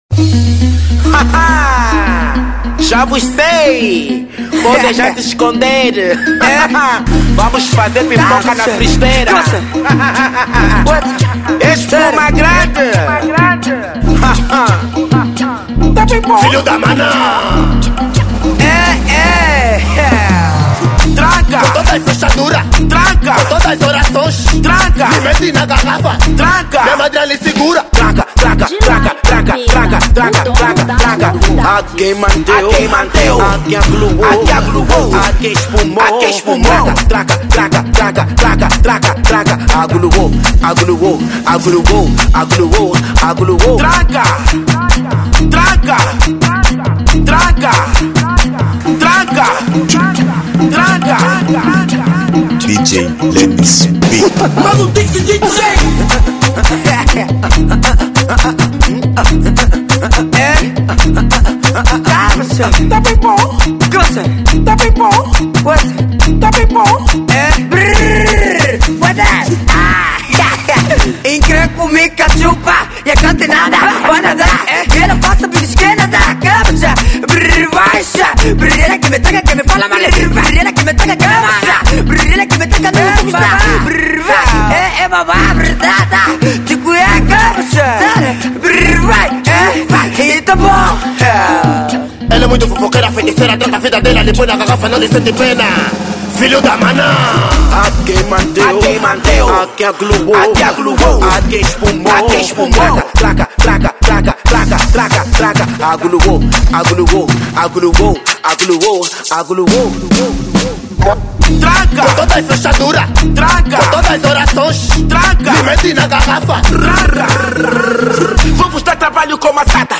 Afro House 2025